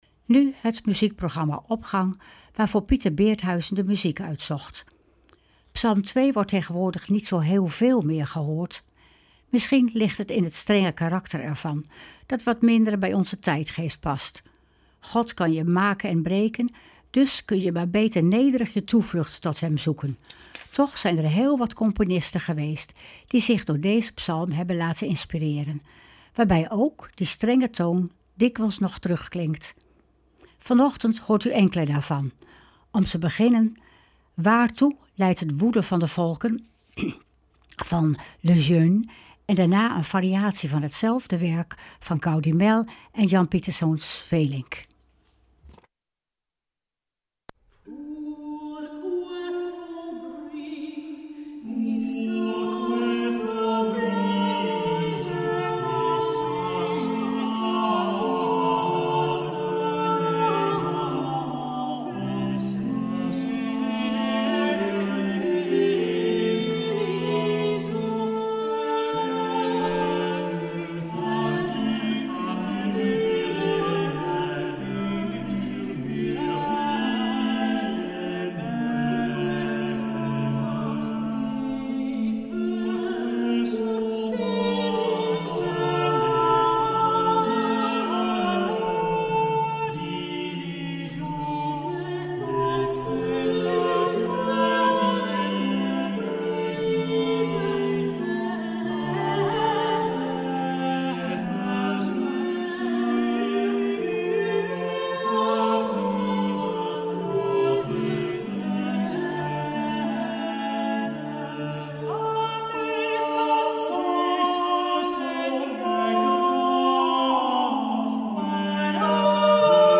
Opening van deze zondag met muziek, rechtstreeks vanuit onze studio.
Niettemin zijn er heel wat componisten geweest die zich door deze Psalm hebben laten inspireren, waarbij ook die strenge toon vaak nog terug klinkt.